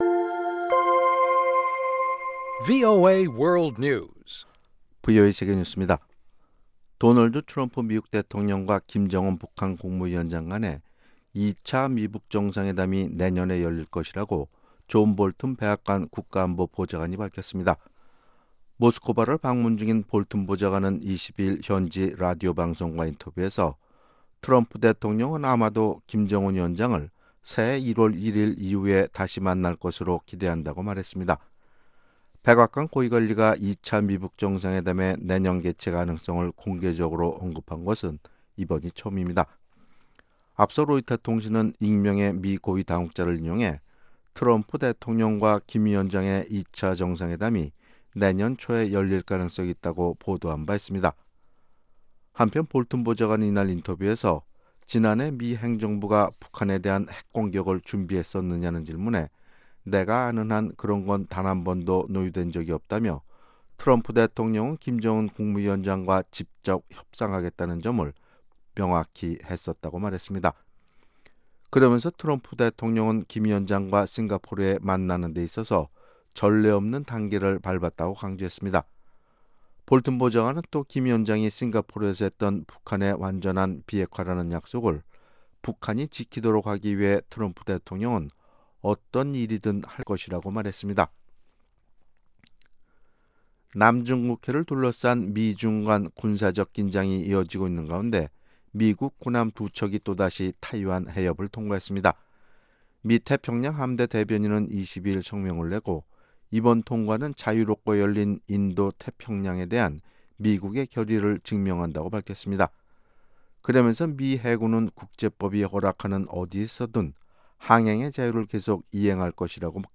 VOA 한국어 아침 뉴스 프로그램 '워싱턴 뉴스 광장' 2018년 10월 24일 방송입니다. 존 볼튼 미 백악관 국가안보보좌관은 트럼프 행정부가 북한에 대한 핵 공격을 논의한 적이 없으며 2차 미-북 정상회담은 새해 이후에 열릴 것이라고 말했습니다. 유럽 연합은 북한의 핵 시설 검증은 중요하며, 북한은 이와 관련한 국제기구의 활동을 받아들여야 한다고 밝혔습니다.